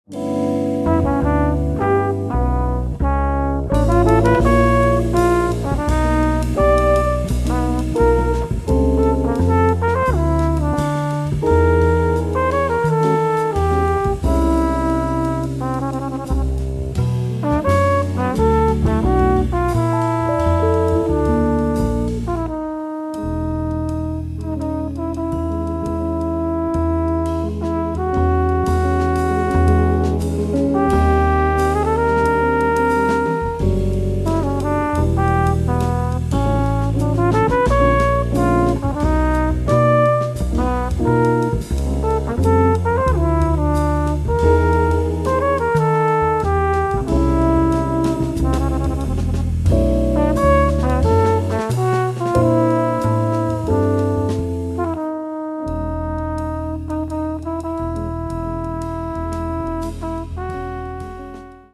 Catalogue -> Jazz & almost -> Quiet emotions